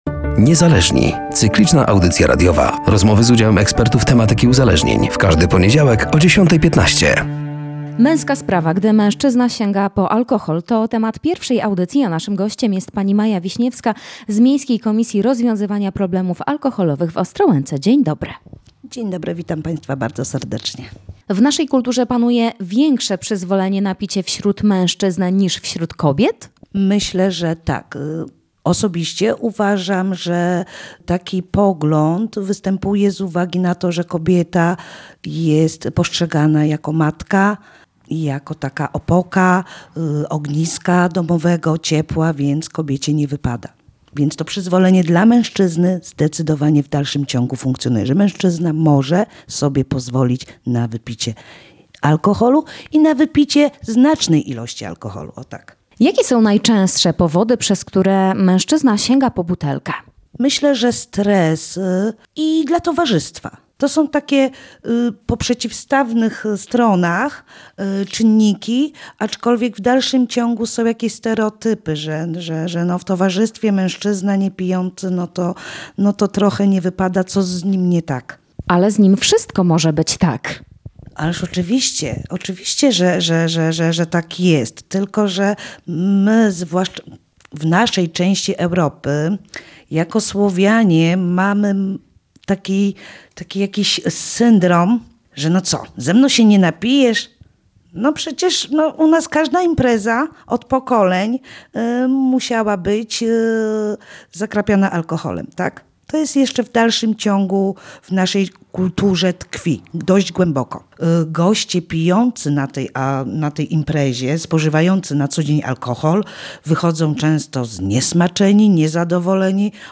“Niezależni” cykliczna audycja radiowa